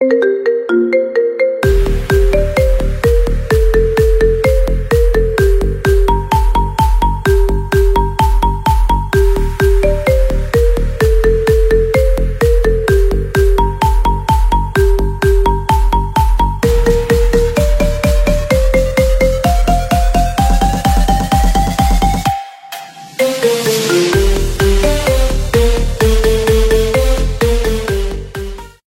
Kategorien: Marimba Remix